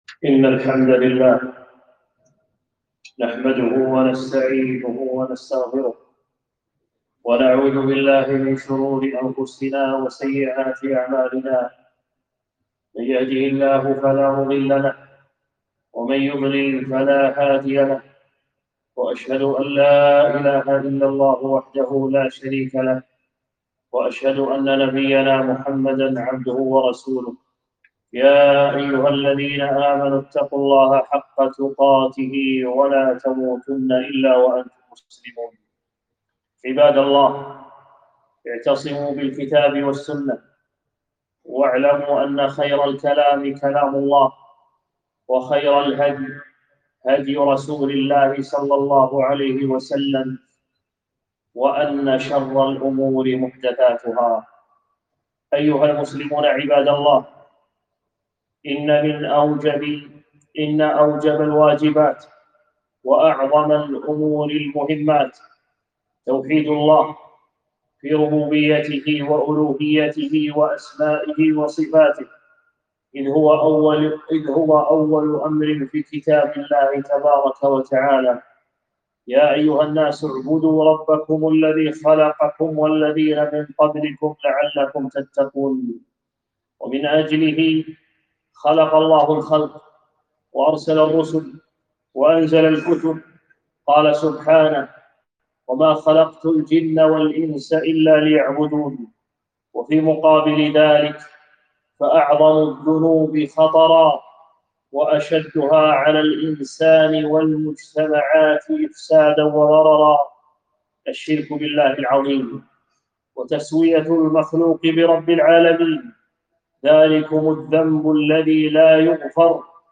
خطبة - الشرك الأصغر